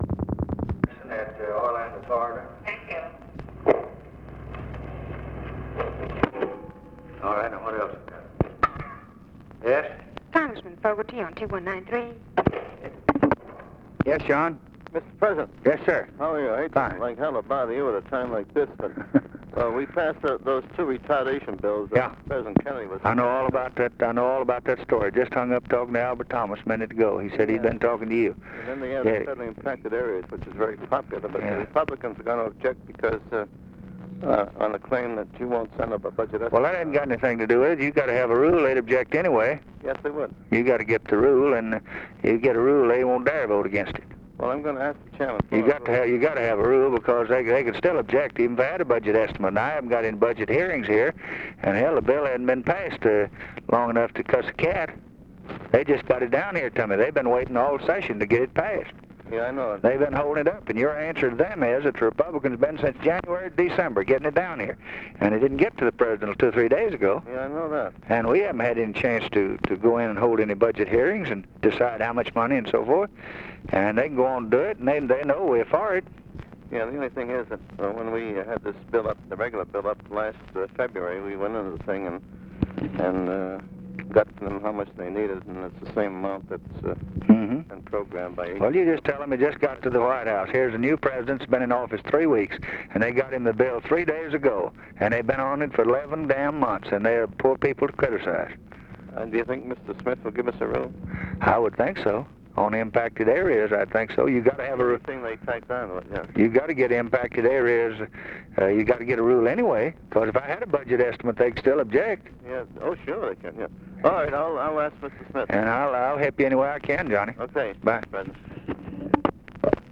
Conversation with JOHN FOGARTY, December 20, 1963
Secret White House Tapes